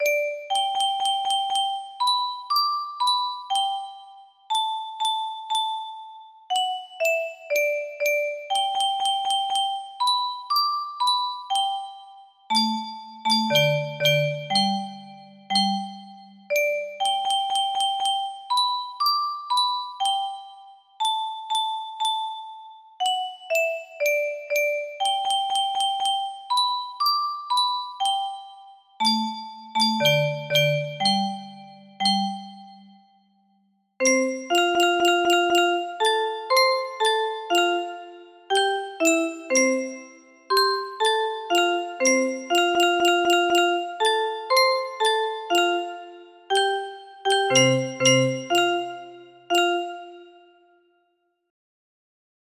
De wielen van de bus music box melody